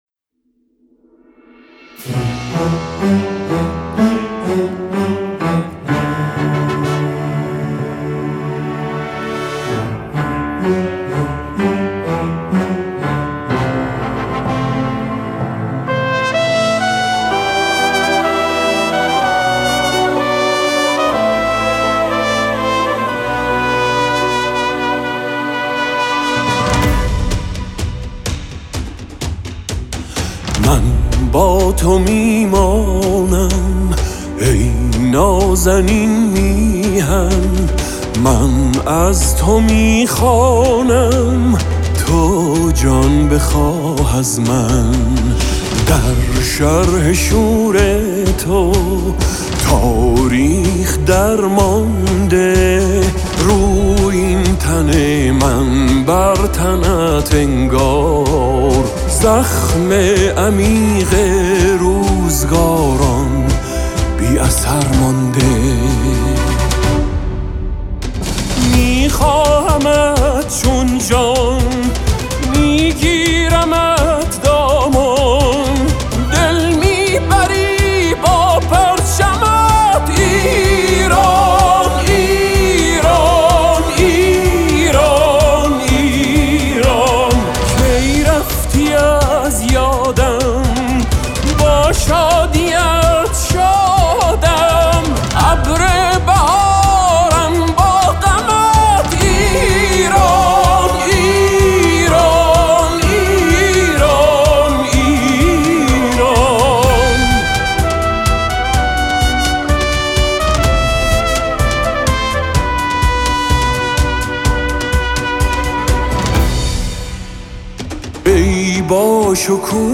این بار هم آنها با آهنگی ملی میهنی به میدان آمده‌اند.